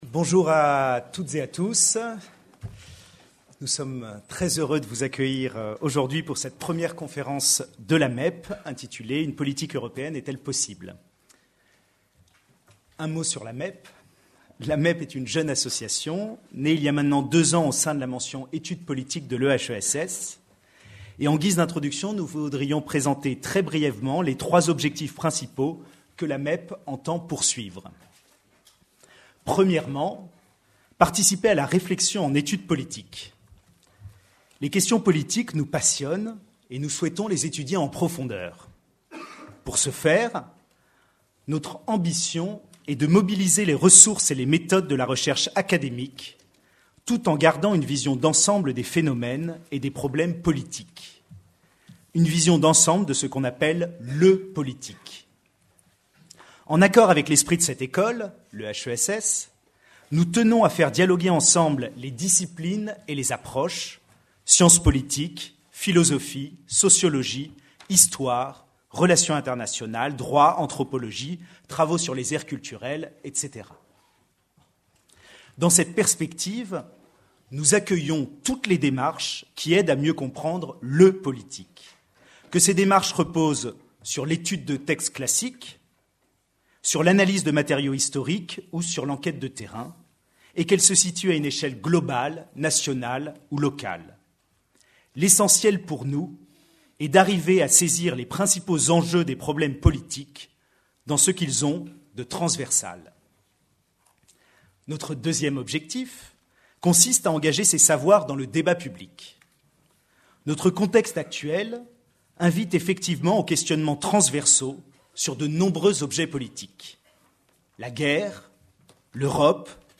Conférence organisée par l'Association des Amis de la Mention Etudes politiques Notre questionnement puise à la source de deux constats incontournables résultant de l’actualité européenne de l’année en cours.